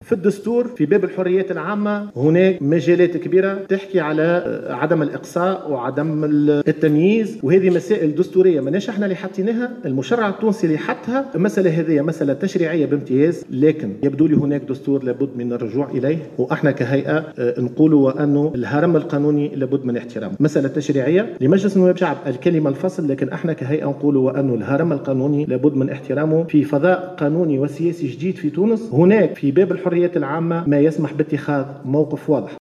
وأضاف بفون خلال ندوة صحفية نظمتها الهيئة اليوم الأربعاء للإعلان عن روزنامة الانتخابات التشريعية والرئاسية ، إن الهيئة تحترم القانون، ومادام الدستور لم يقصي التجمعيين فإنها لا تستطيع إقصاء أي كان منهم من المكاتب الفرعية.